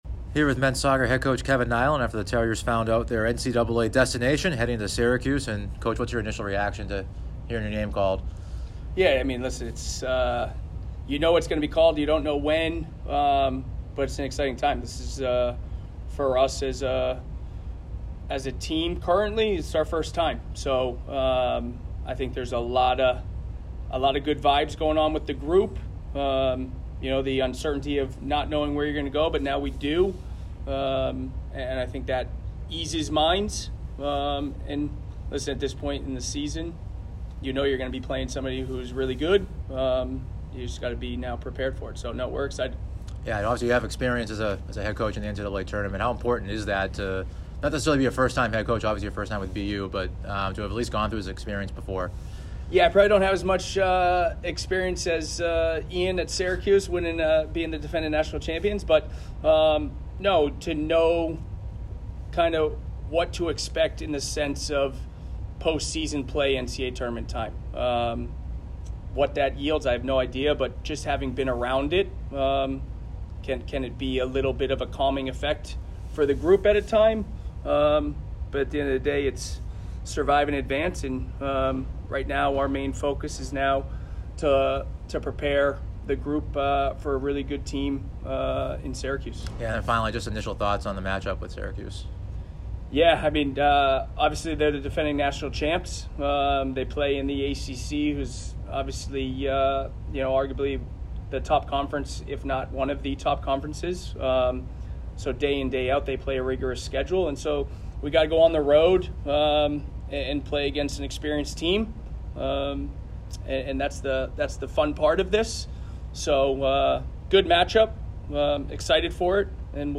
NCAA Selection Show Interview